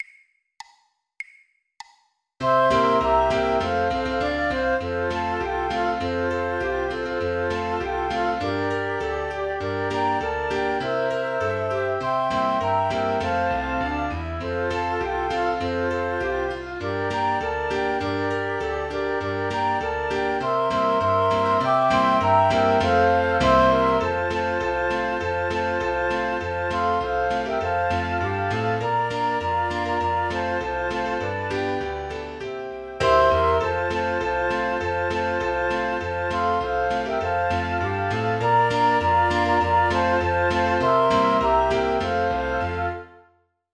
Playback Crowd 2 100 bpm
jingle_bells_Flöte_Klavier.mp3